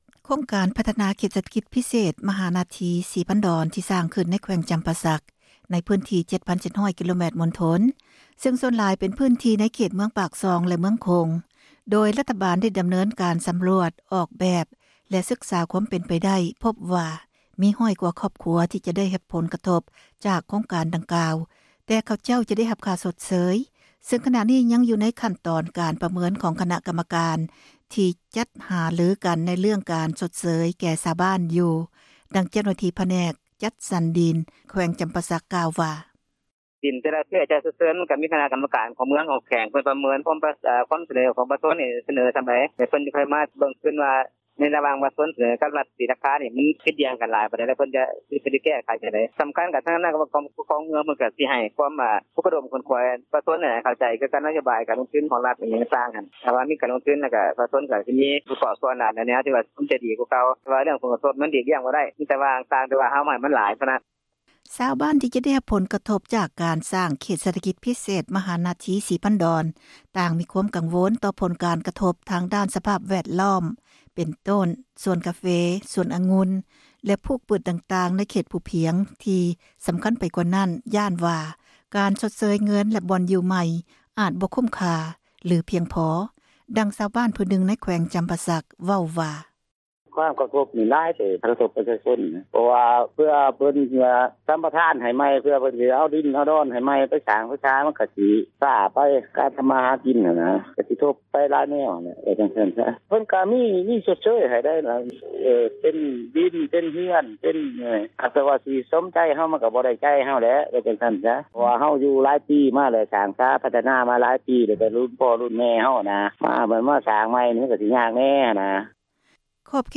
ຊາວບ້ານທີ່ຈະໄດ້ຮັບຜົນກະທົບ ຈາກການສ້າງເຂດເສຖກິດພິເສດ ມະນະທີສີພັນດອນ ຕ່າງມີຄວາມກັງວົນ ຕໍ່ຜົນກະທົບທາງດ້ານສະພາບ ແວດລ້ອມ ເປັນຕົ້ນສວນກາເຟ, ສວນອາງຸ່ນ, ພຶດປູກຕ່າງໆທີ່ເຂດພູພຽງ ແລະທີ່ສໍາຄັນໄປກວ່ານັ້ນ ກໍຄືຢ້ານວ່າ ການຊົດເຊີຍເງິນ ແລະ ບ່ອນຢູ່ໃໝ່ ອາດບໍ່ຄຸ້ມຄ່າຫ ລືພຽງພໍ, ດັ່ງຊາວບ້ານຜູ້ນຶ່ງ ໃນແຂວງຈໍາປາສັກ ເວົ້າວ່າ: